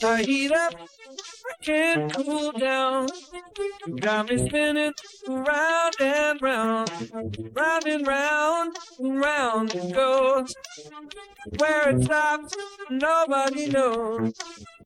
Here’s a sample of a stereo mix which worked so impressively that I uploaded the instrumental estimation over on YouTube (I linked it back on page 1. The only modifications I made is I added the instrumental portions to it so it goes between the original and the estimation).